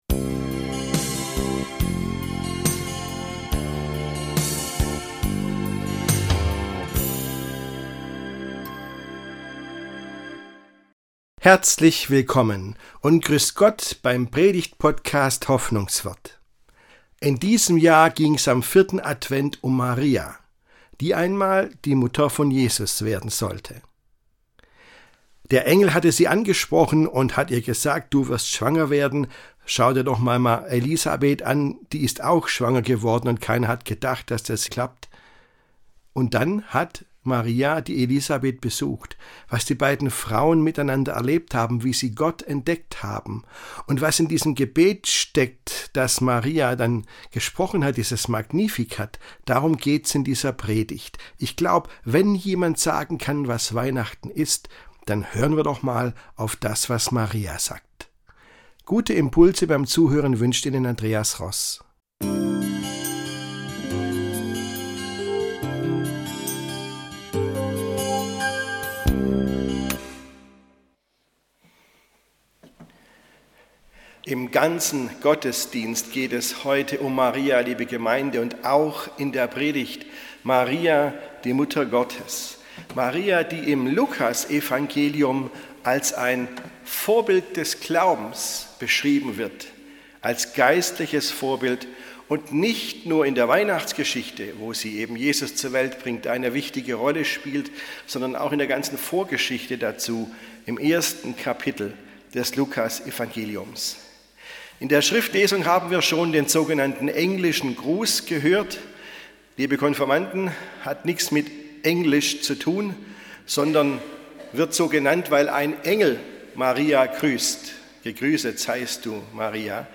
Welche Maria!? - Die Welt auf den Kopf gestellt ~ Hoffnungswort - Predigten